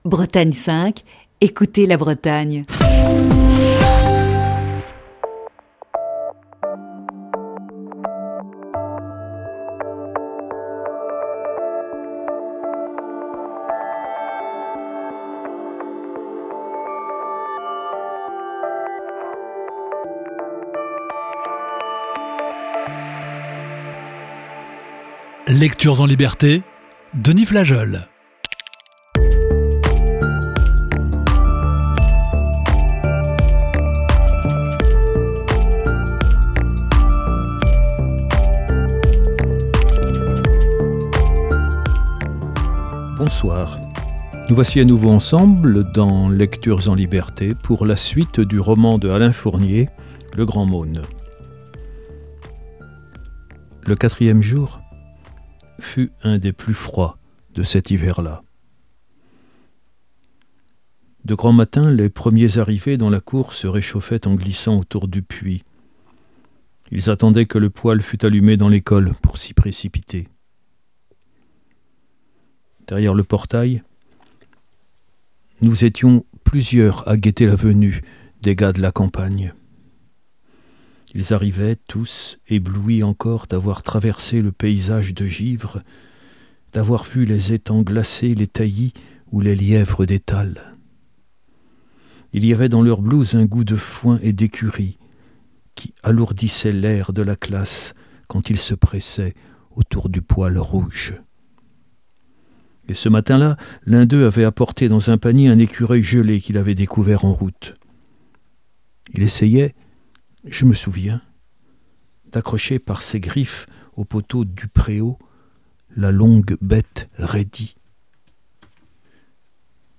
poursuit la lecture d'un classique de la littérature "Le Grand Meaulnes" d'Alain Fournier.